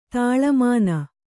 ♪ tāḷa māna